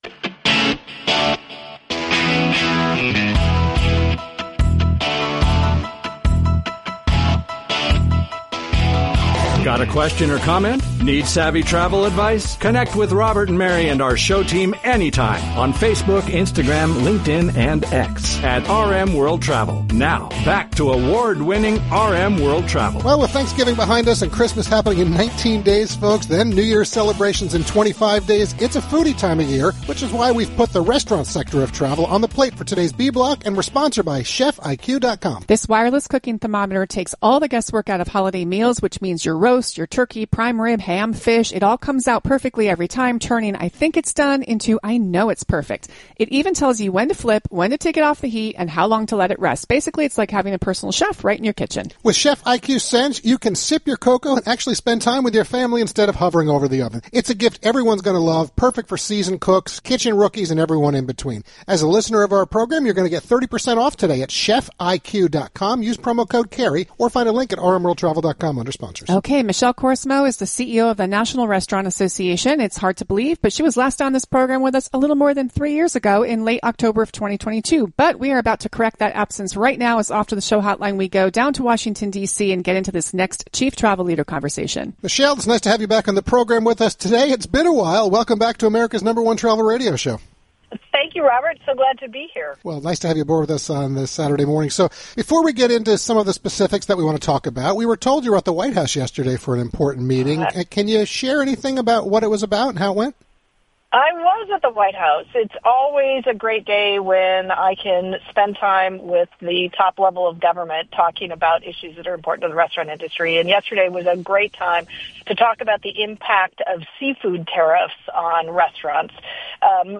There’s been a lapse since that initial visit that we corrected during the latest “Chief Travel Leader” conversation that occurred as part of the live national broadcast on 6 December 2025.